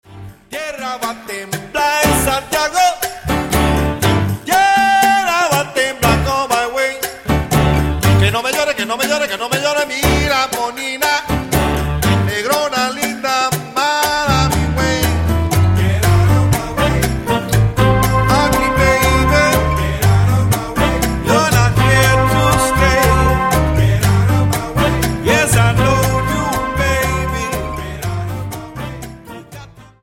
Cha Cha Song